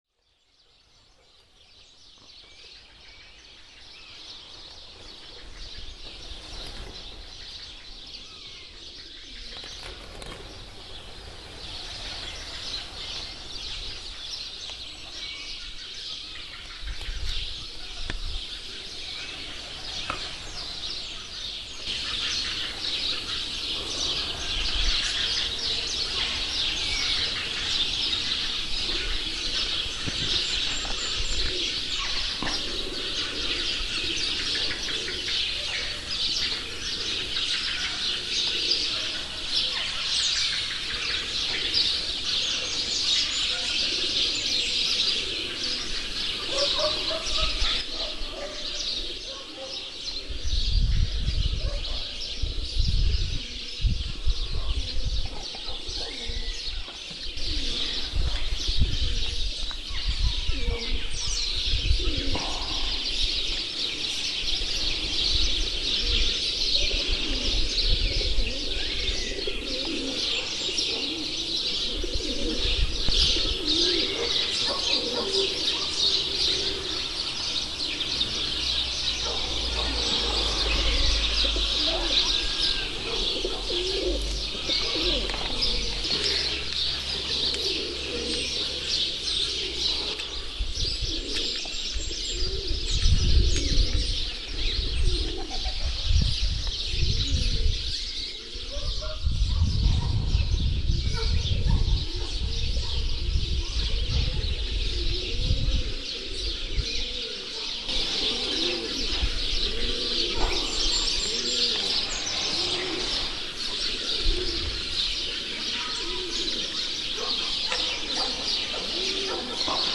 Nei giorni della manifestazione, chiunque percorresse gli scalini che conducono alla cripta attivava dei sensori che azionavano la registrazione sonora.
2009 il canto degli uccelli sui miei passi.mp3